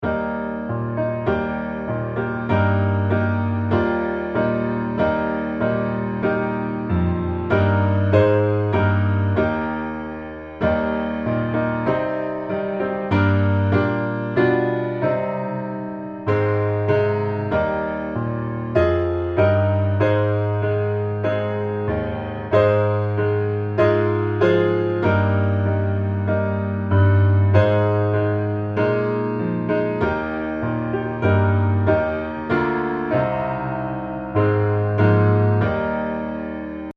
Negro Spiritual
D Major